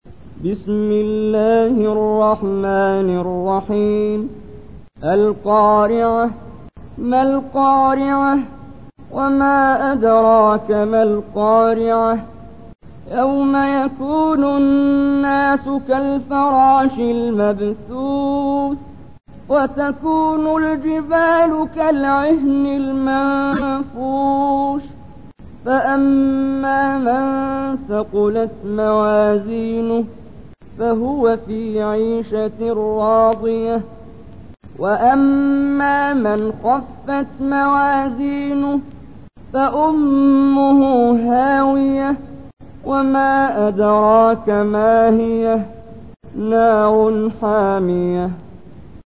تحميل : 101. سورة القارعة / القارئ محمد جبريل / القرآن الكريم / موقع يا حسين